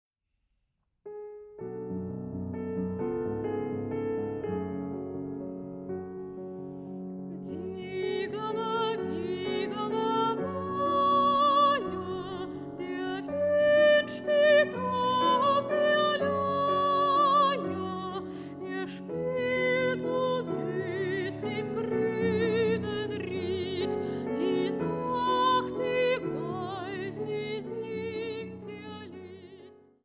Sopranistin
Pianistin